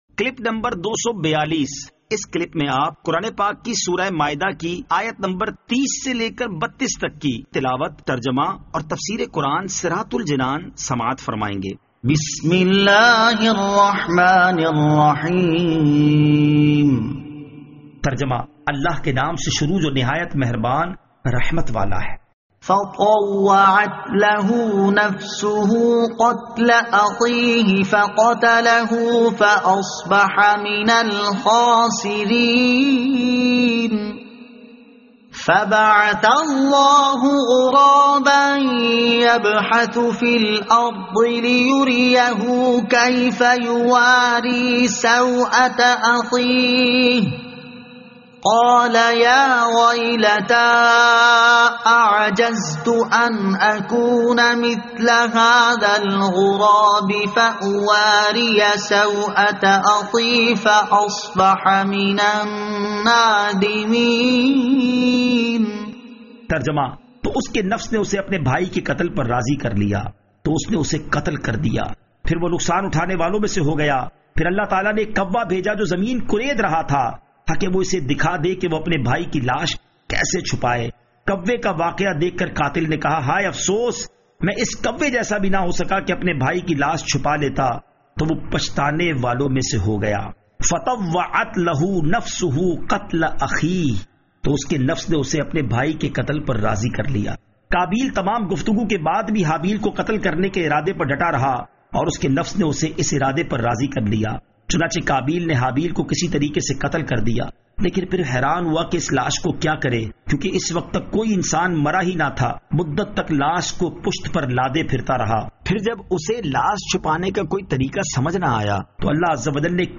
Surah Al-Maidah Ayat 30 To 32 Tilawat , Tarjama , Tafseer